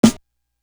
Still Feel Me Snare.wav